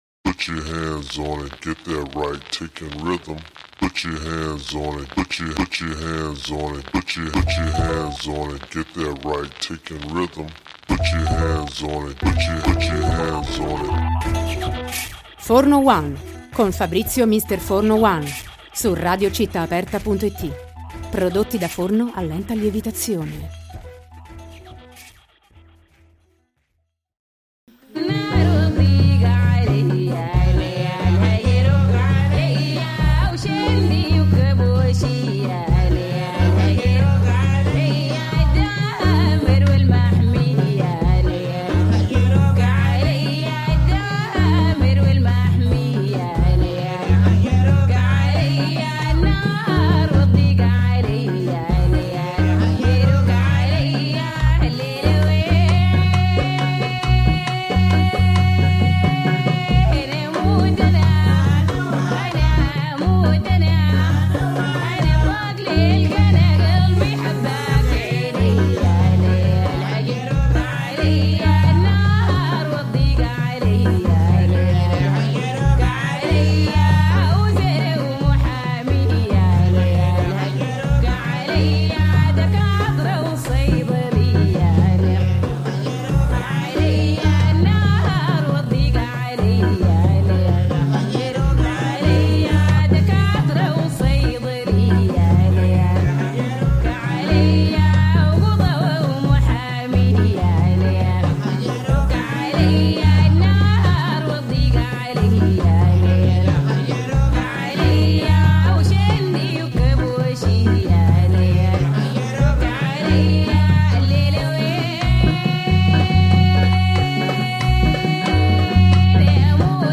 Intervista_The-Scorpios.mp3